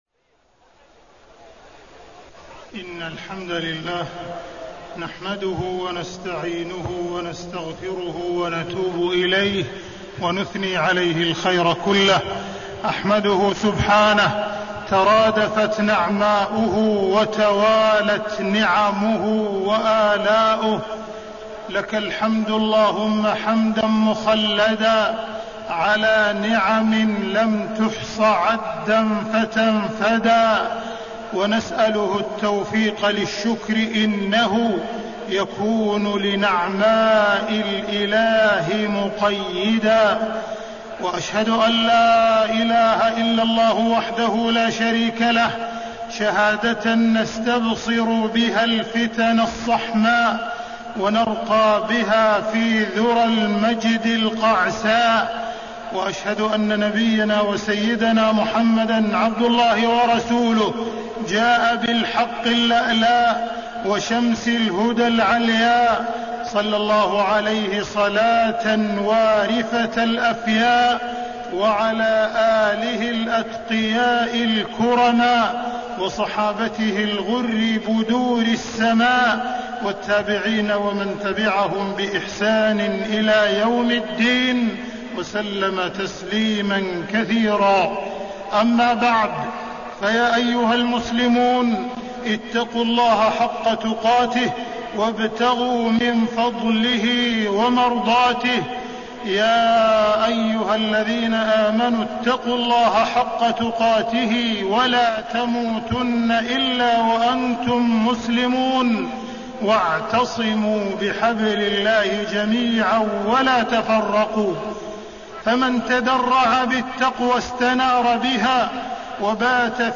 تاريخ النشر ٢٩ رمضان ١٤٣٣ هـ المكان: المسجد الحرام الشيخ: معالي الشيخ أ.د. عبدالرحمن بن عبدالعزيز السديس معالي الشيخ أ.د. عبدالرحمن بن عبدالعزيز السديس كلمة في ختام الشهر الفضيل